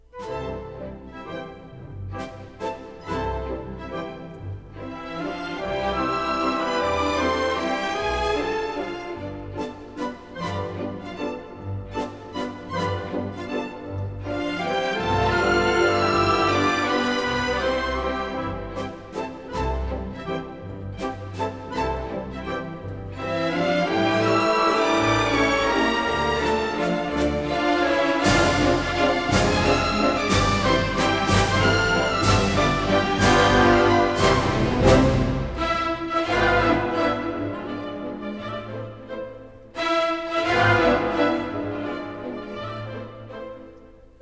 Stereo recording made in April 1960 in the
Orchestral Hall, Chicago